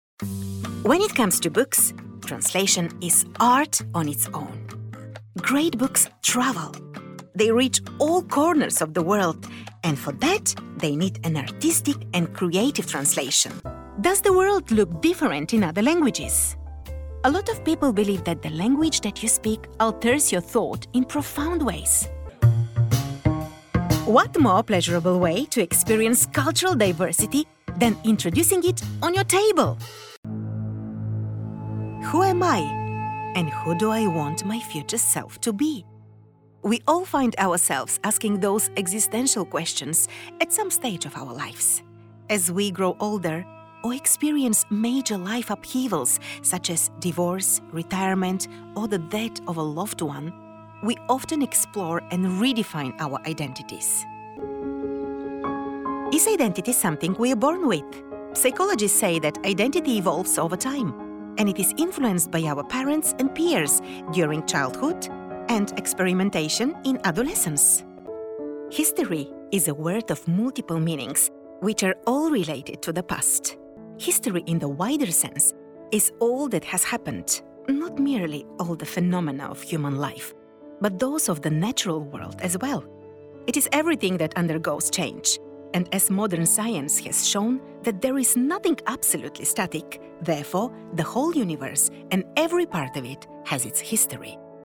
Female
Yng Adult (18-29), Adult (30-50)
Narration